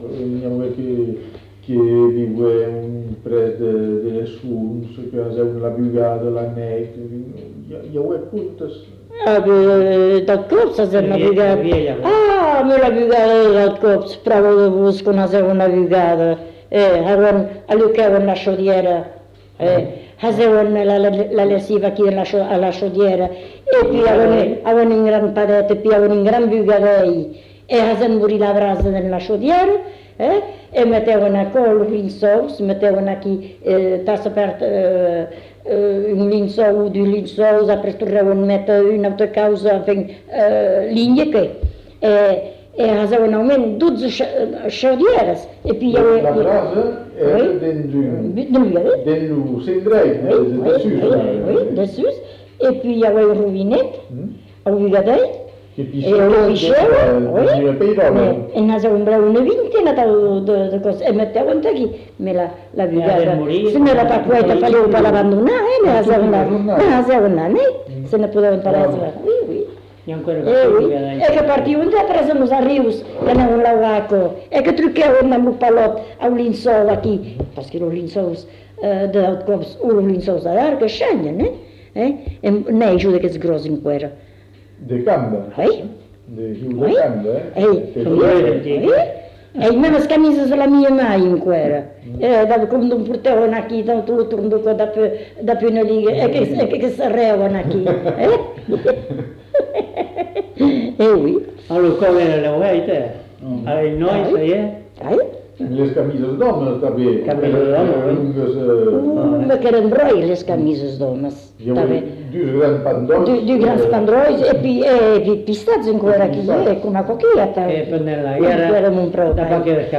Lieu : Cazalis
Genre : témoignage thématique
Notes consultables : L'informatrice n'est pas identifiée.